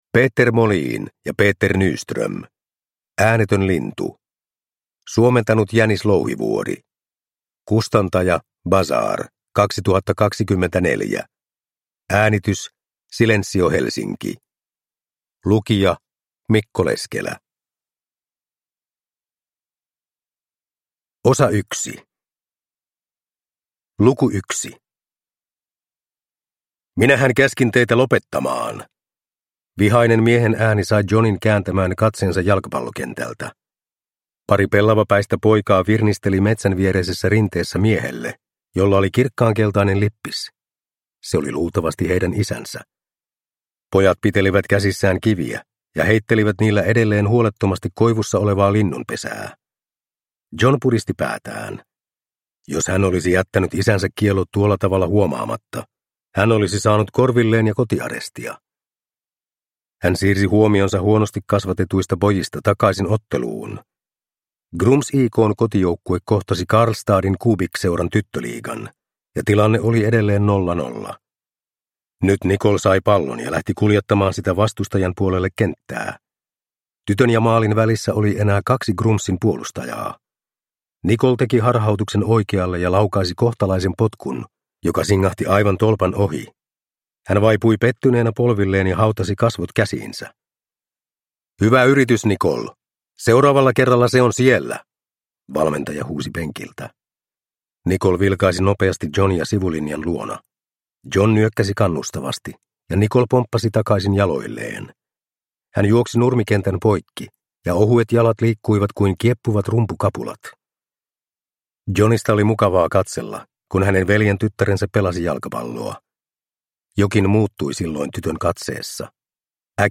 Äänetön lintu – Ljudbok